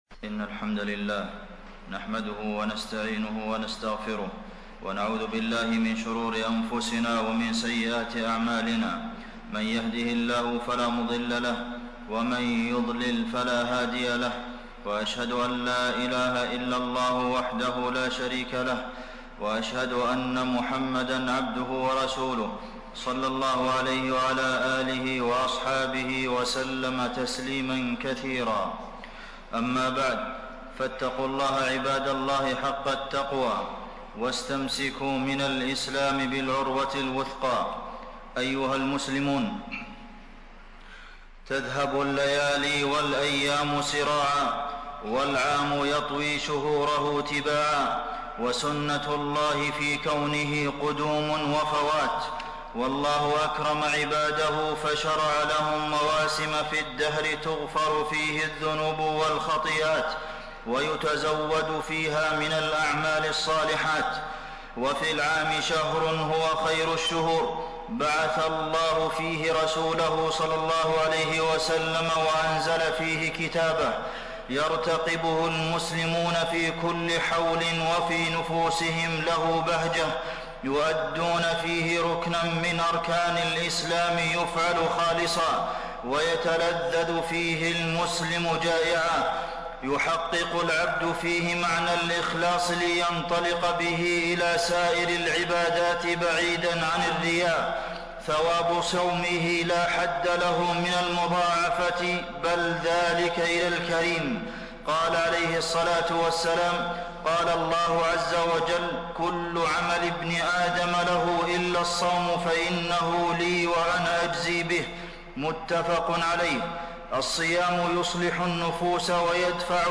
تاريخ النشر ٢١ شعبان ١٤٣٢ هـ المكان: المسجد النبوي الشيخ: فضيلة الشيخ د. عبدالمحسن بن محمد القاسم فضيلة الشيخ د. عبدالمحسن بن محمد القاسم نفحات شهر رمضان